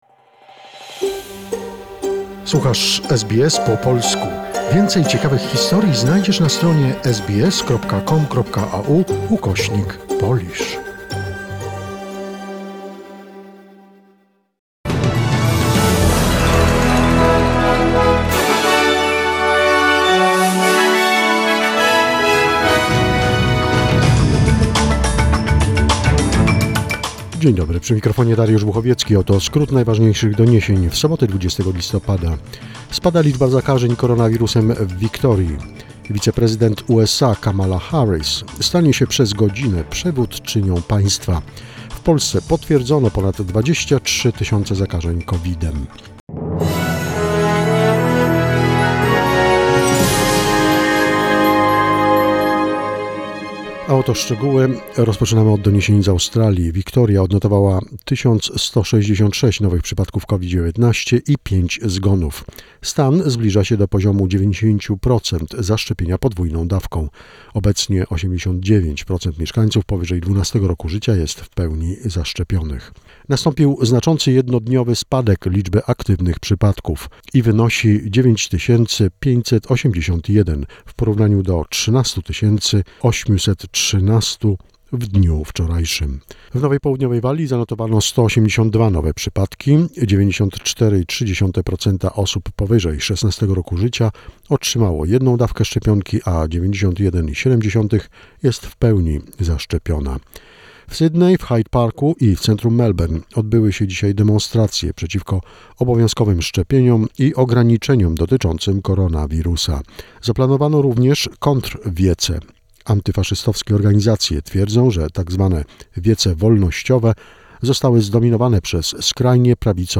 SBS News Flash in Polish, 20 November 2021